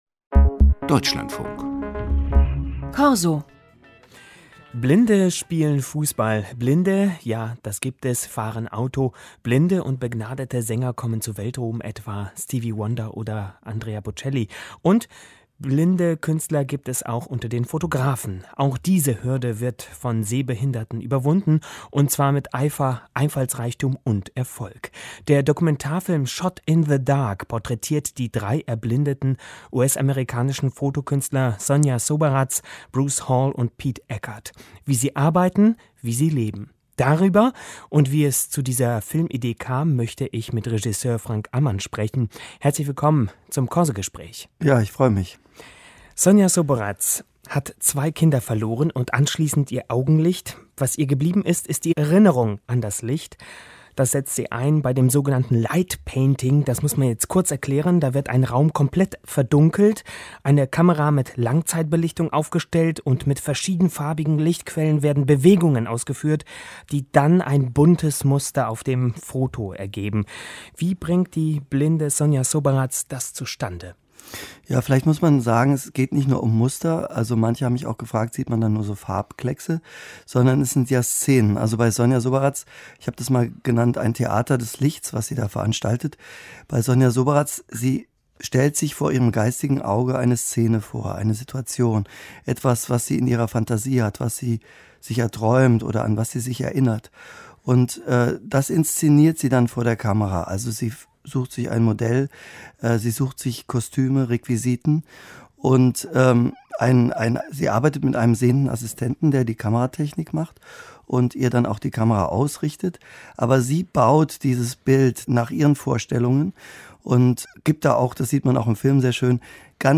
(Radio broadcast, in German)